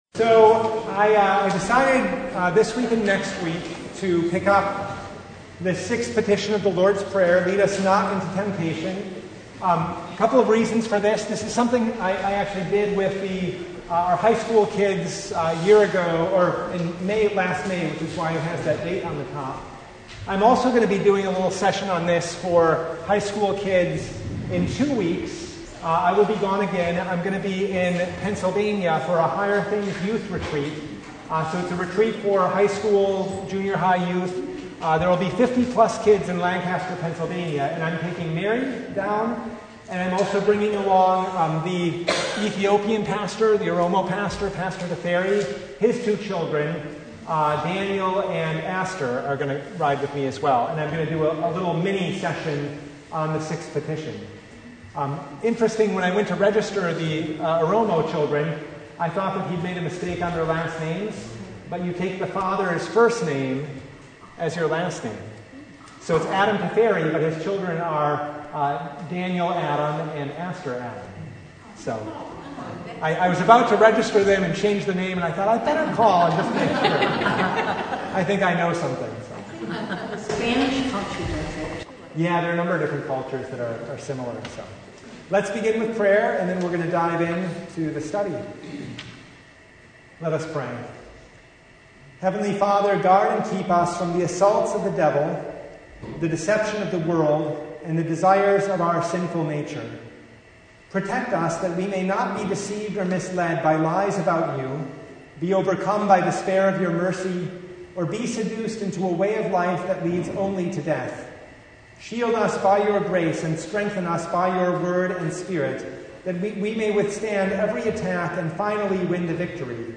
Service Type: Bible Hour